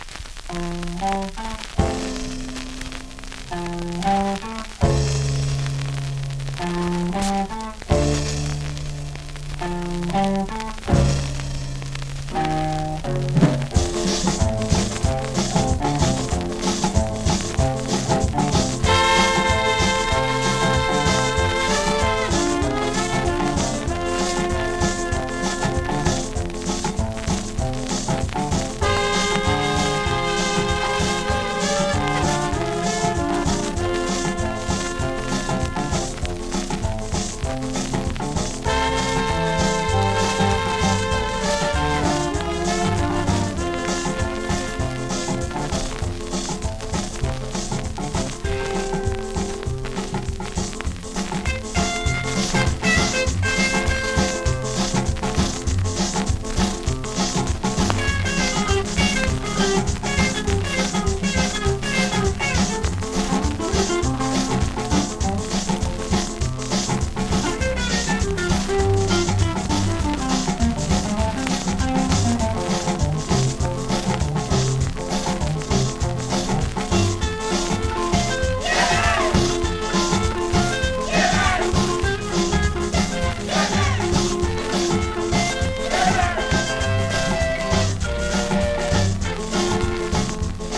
いけそうな感じです)   コメントMEGA RARE CALYPSO!!
スリキズ、ノイズそこそこあります。